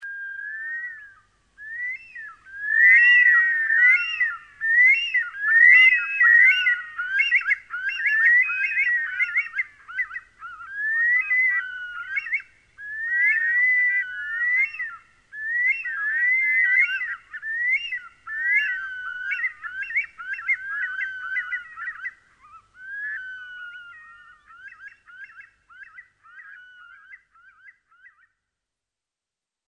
Bush Stone-curlew call - Audio
bush-stone-curlew-call-audio.mp3